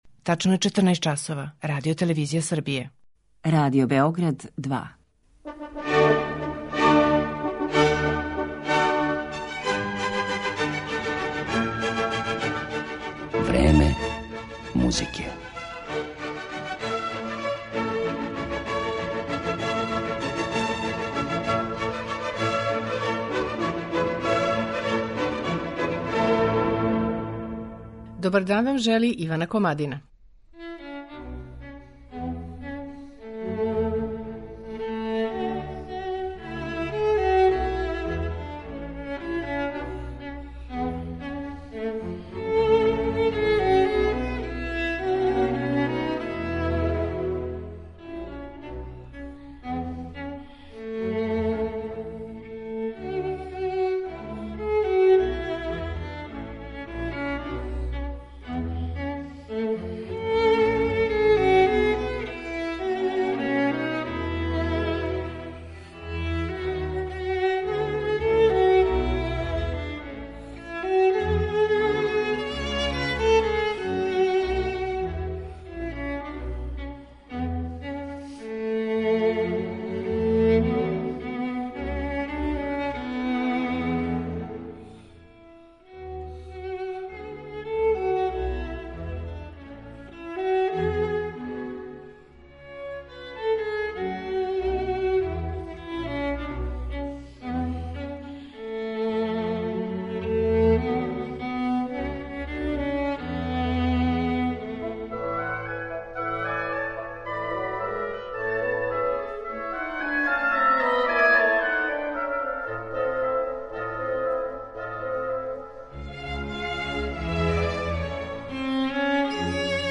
Виолиниста Максим Рисанов
Више од две деценије наступа у најугледнијим светским концертним дворанама, сарађује са највећим окрестрима и диригентима, а композиторе подстиче да пишу нова дела за његов инструмент. У данашњем Времену музике представићемо га интерпретацијама дела Франца Шуберта, Петра Чајковског, Макса Бруха и Добринке Табакове.